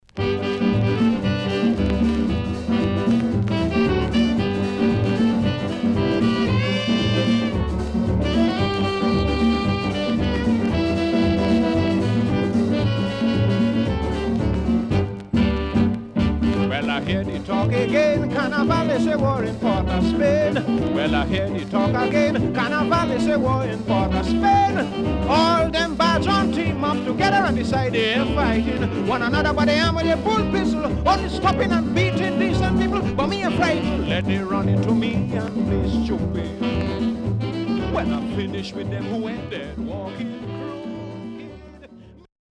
W-Side Good Calypso
SIDE B:所々チリノイズがあり、少しプチノイズ入ります。